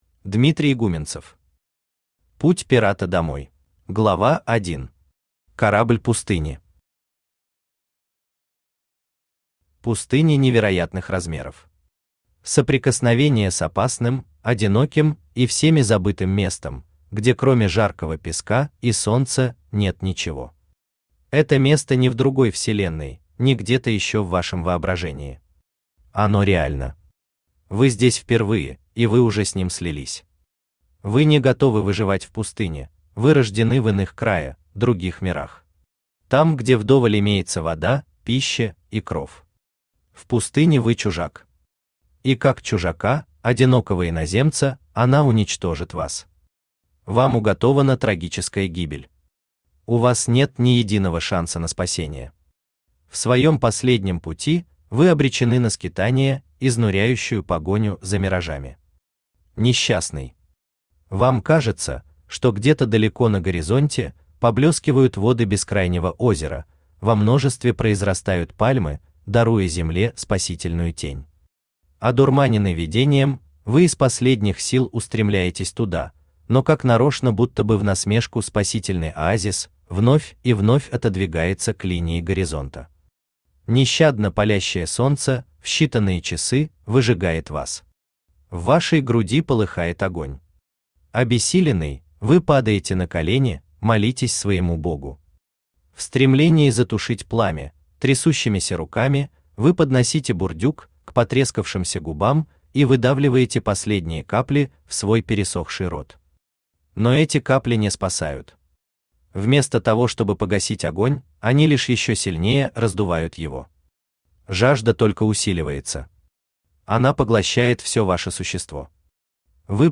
Аудиокнига Путь пирата домой | Библиотека аудиокниг